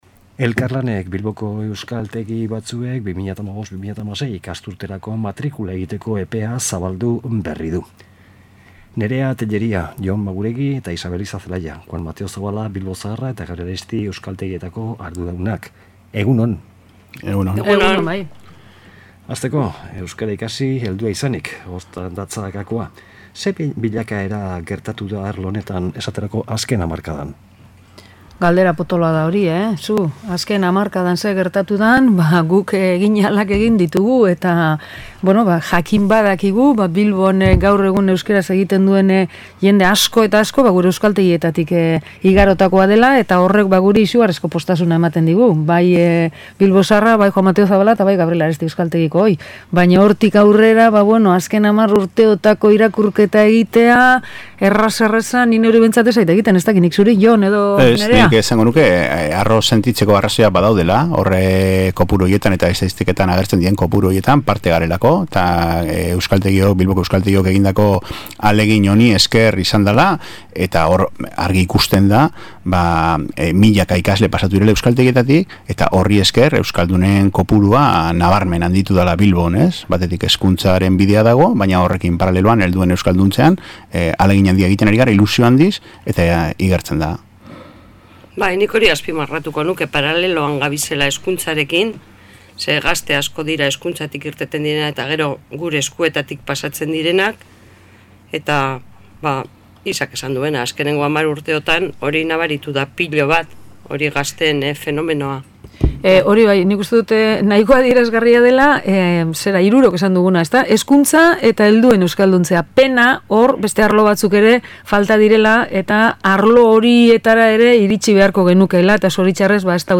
Bisita berezia izan dugu gaur gure irratian